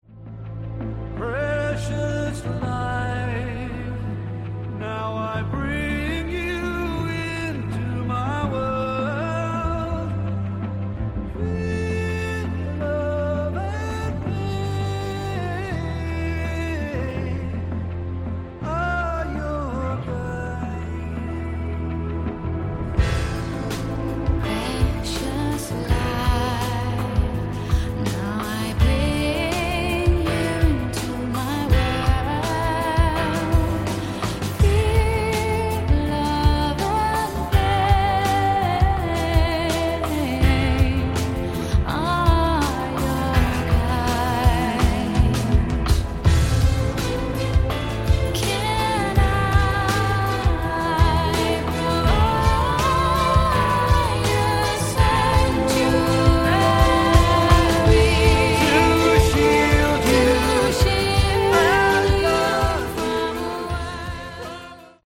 Category: AOR
vocals, bass guitar, acoustic guitar, keyboards